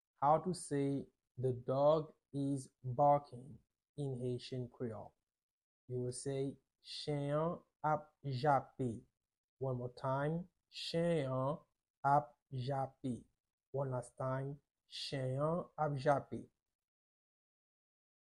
Listen to and watch “chen an ap jape” audio pronunciation in Haitian Creole by a native Haitian  in the video below: